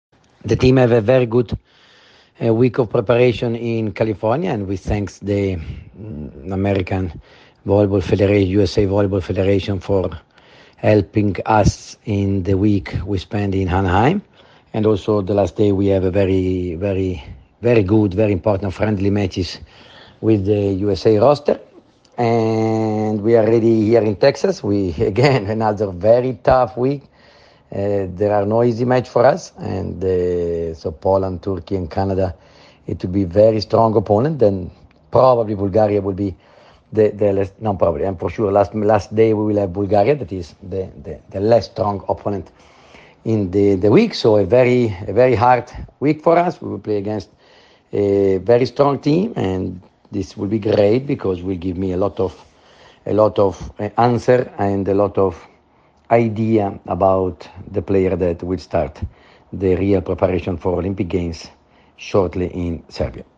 Izjava Đovanija Gvidetija